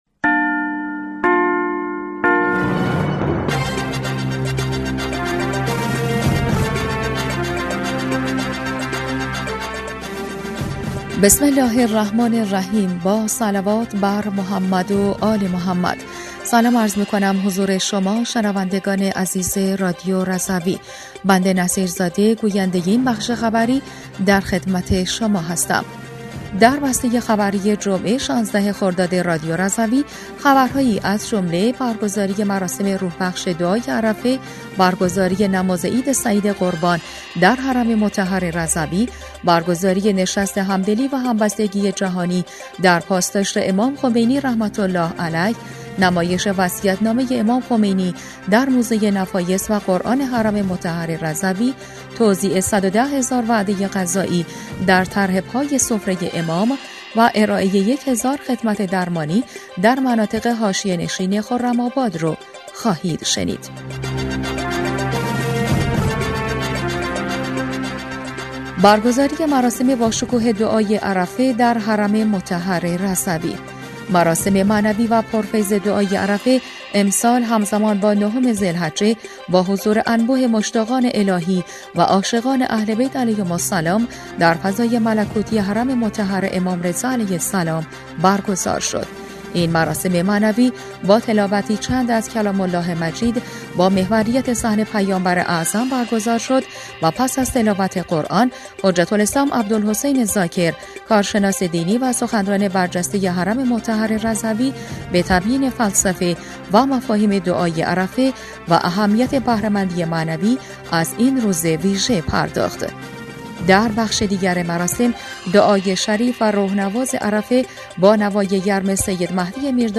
بسته خبری